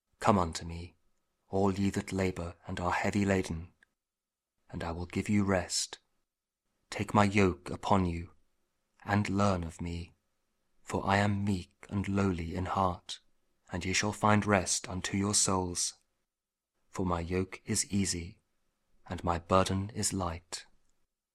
Gospel Reading: Matthew 11:28-30